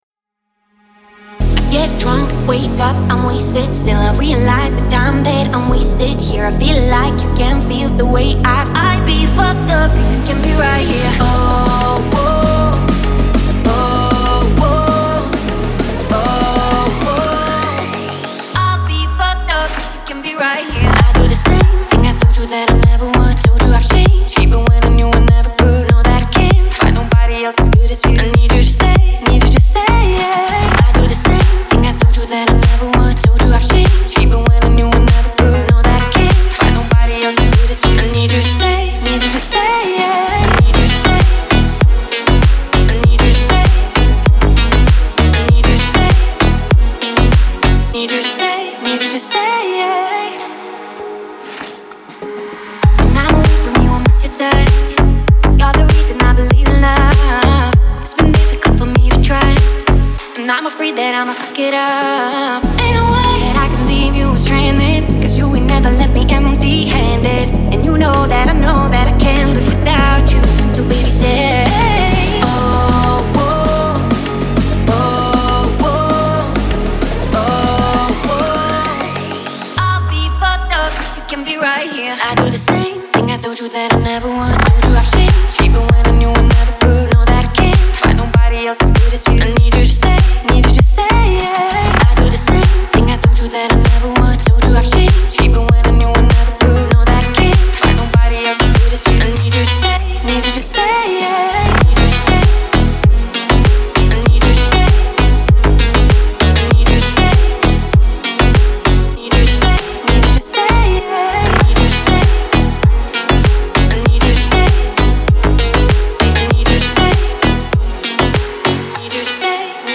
Хорошие модные ремиксы
клубные песни